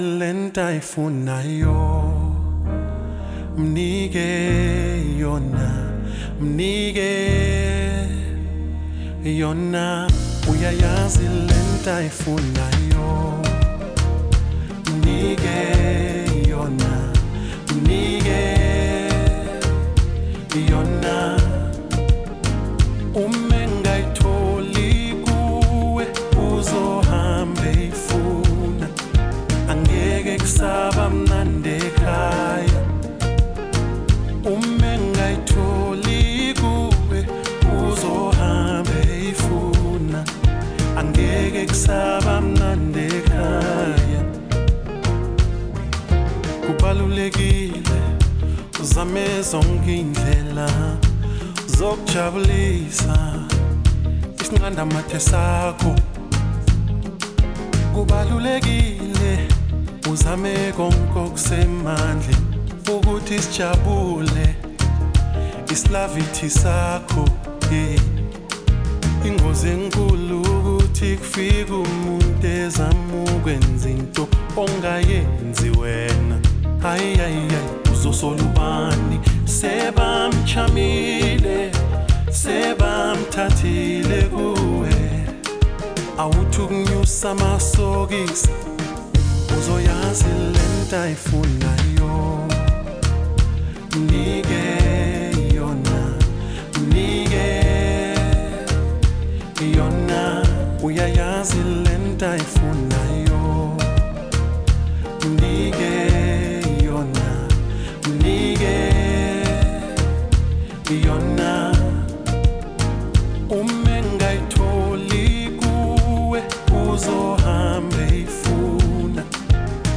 Genre : Afro House